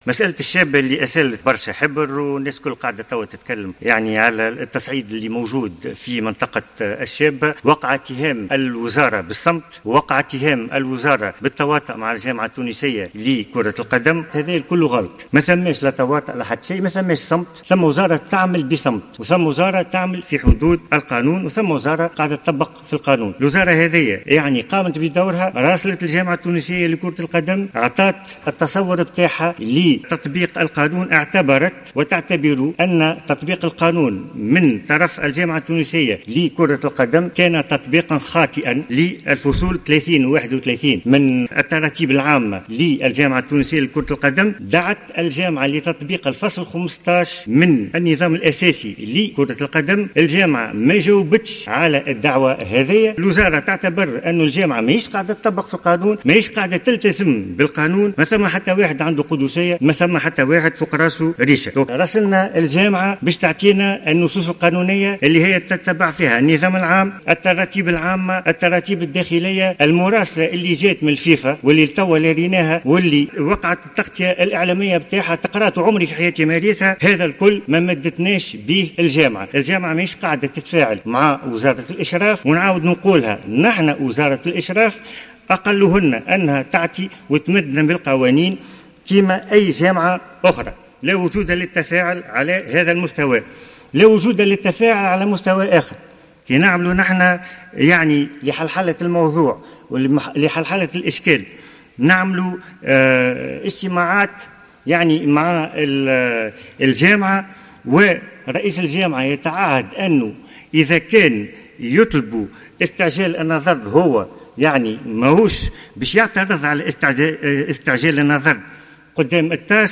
نفى وزير شؤون الشباب والرياضة والإدماج المهني، كمال دقيش، في رده على تساؤلات النواب، خلال جلسة مناقشة ميزانية الوزارة، في مجلس نواب الشعب، الخميس، صمت الوزارة أو تواطئها مع جامعة كرة القدم، فيما يتعلق بملف هلال الشابة.